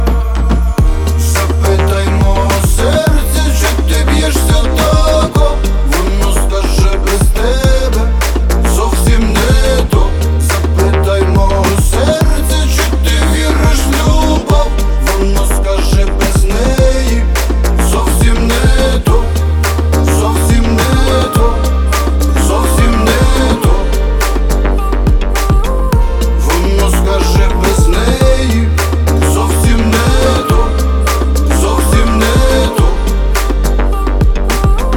Hip-Hop Rap Pop